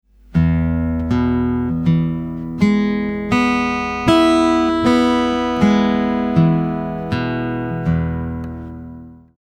Fingerstyle technique is an awesome way to add texture and nuance to your guitar playing and is used by tons of different guitar players.
thumb-and-first-finger-exercise-1-.mp3